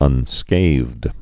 (ŭn-skāthd)